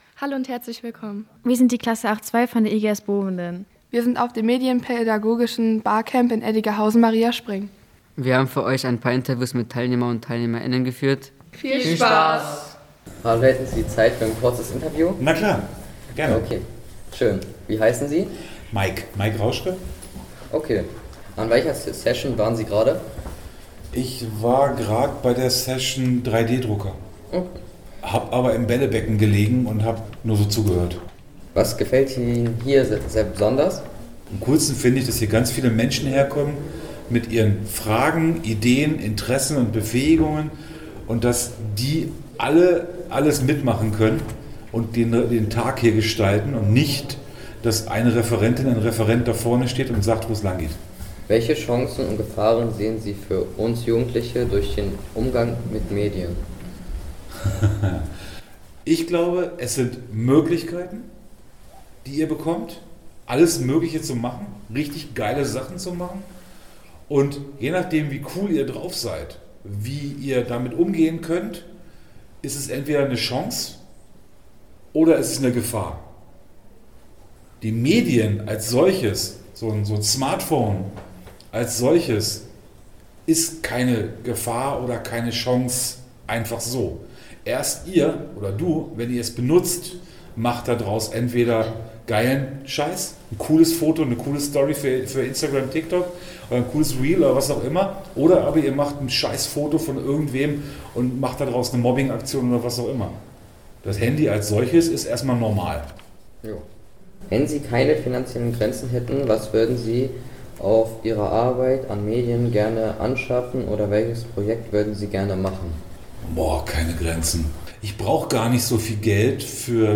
Interviews vom medienpädagogischen Barcamp in Mariaspring